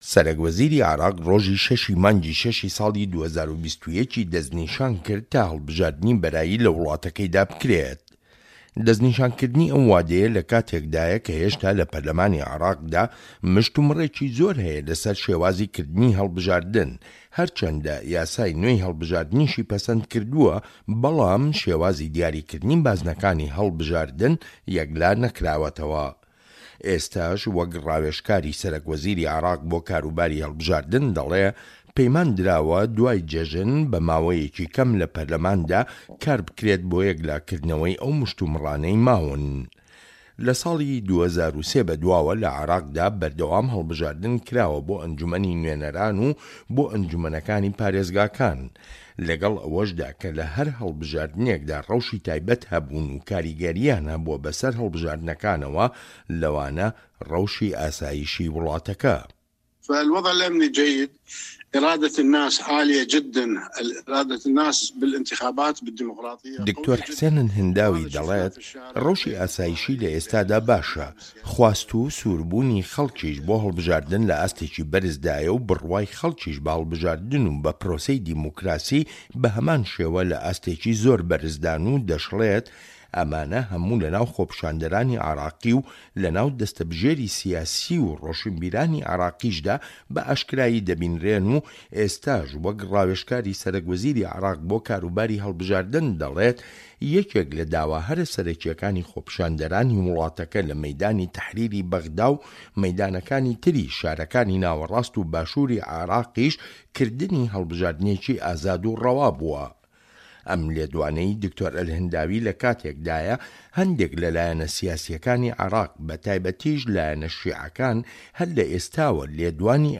ڕاپۆرت لەسەر بنچینەی لێدوانەکانی دکتۆر حسێن ئەلهنداوی